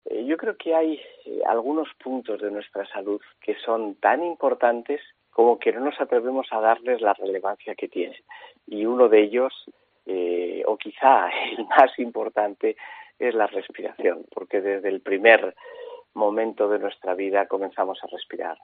Ha publicado un libro que ha presentado en COPE Vitoria y hoy 8 de mayo en una charla